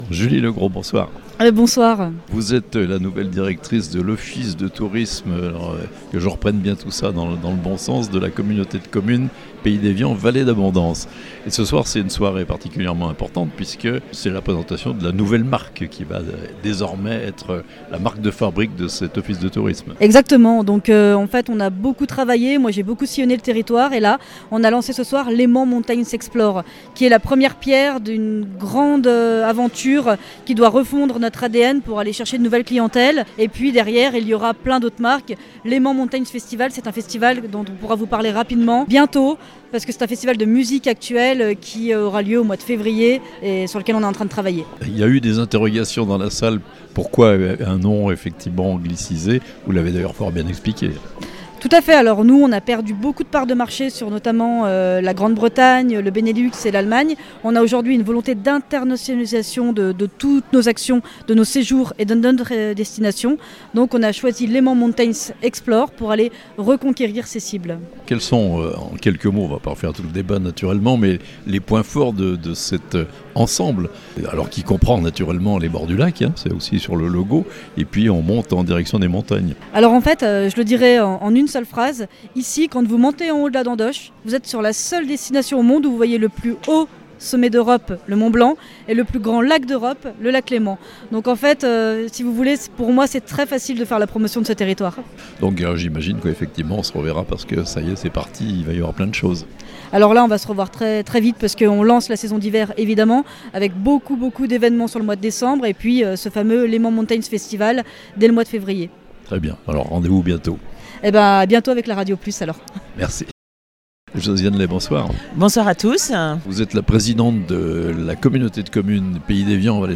Une nouvelle marque pour l'Office de Tourisme du Pays d'Evian-Vallée d'Abondance (interviews)